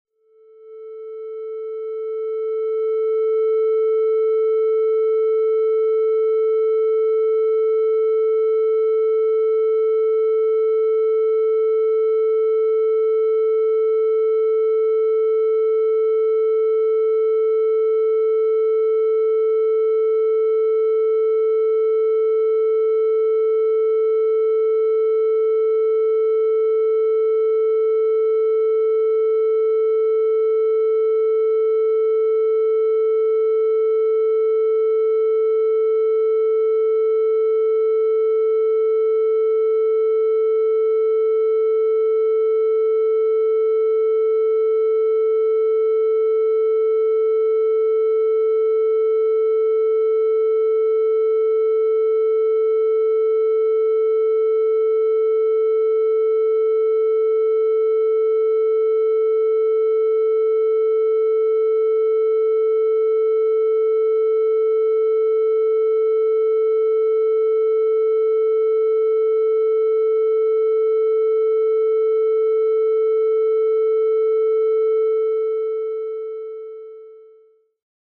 tunerA440.mp3